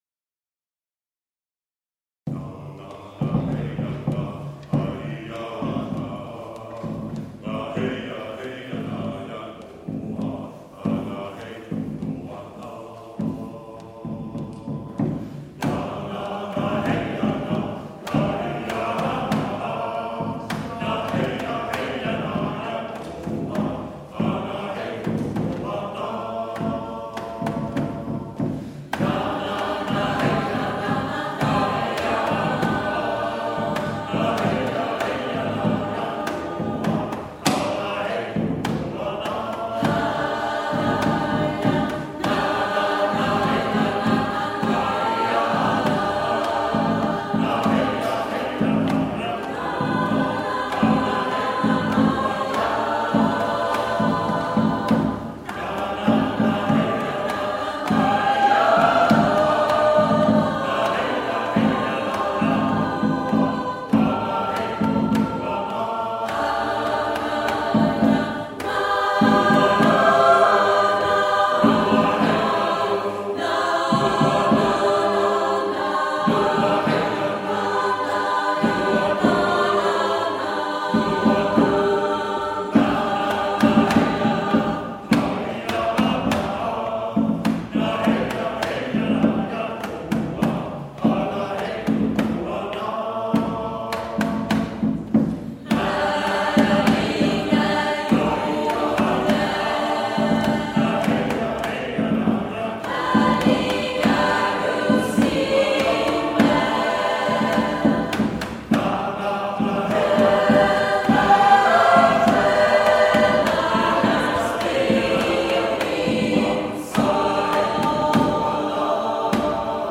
Låten, som heter Eatnemen Vuelie, är skriven av norrmannen Frode Fjellheim med tydlig inspiration från samisk och skandinavisk jojktradition i kombination med en av våra mest kända psalmer, Härlig är jorden. Eatnemen Vuelie med Bygdekören spelades in live vid en konsert i Tåssjö kyrka trettondagen 2017 och lyssna gärna på låten